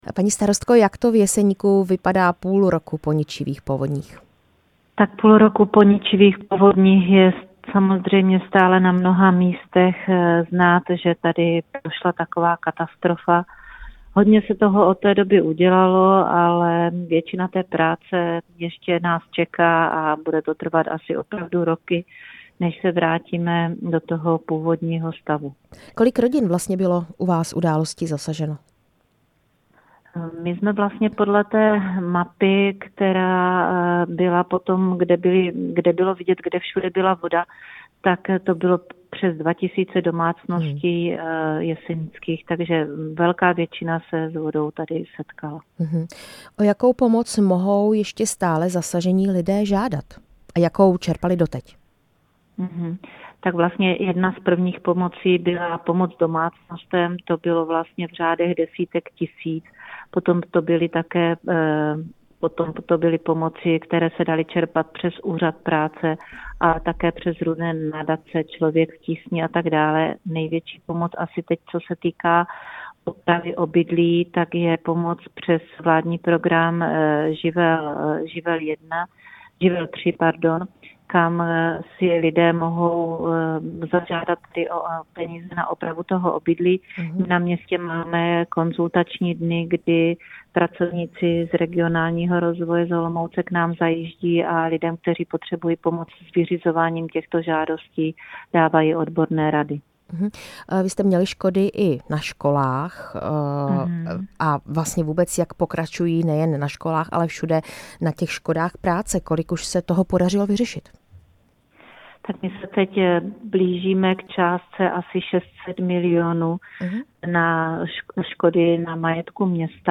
Rozhovor se starostkou Jeseníku Zdeňkou Blišťanovou